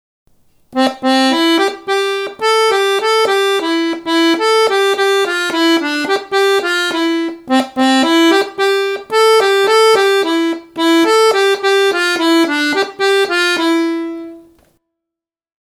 Melodía
melodia_parte_1.mp3